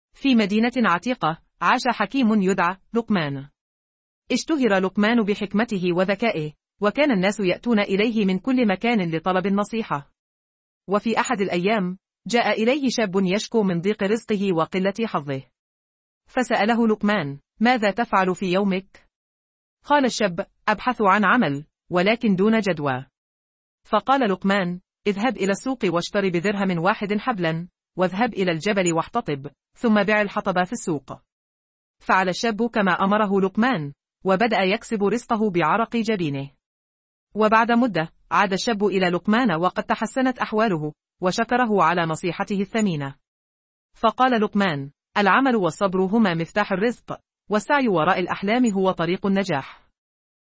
أمثلة لنتائج مولد الأصوات المجاني المتقدم VocalAI
٤. إنشاء قصة صوتية بتعليق أنثوي